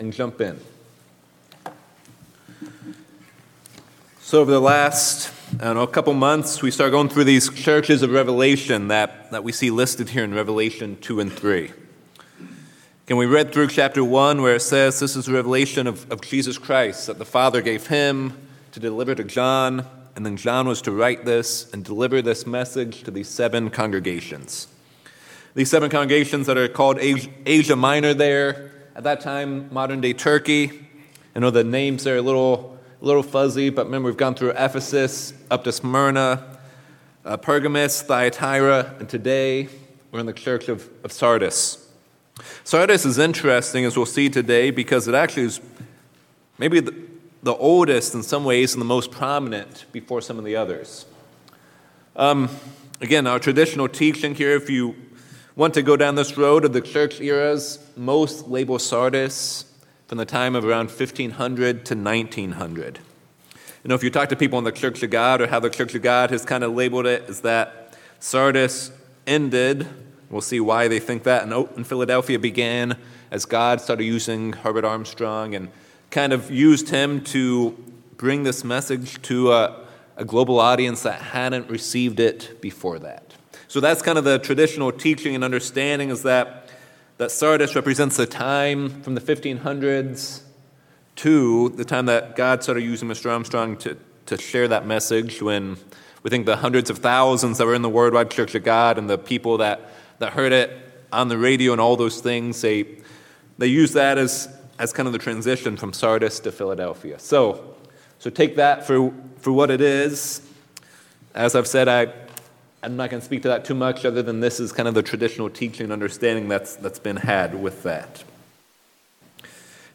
In part five of the sermon series on the seven churches, we will dive deep into the letter to the church of Sardis. This church is known as the dead church, where the congregation is dying and on the brink of extinction. We will see that God gives them a way to turn around through repentance